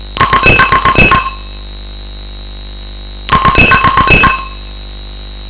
Underwater communication
Elektro Utvikling has developed systems which use sound to communicate between underwater equipment up to 2,5 kilometers in distance.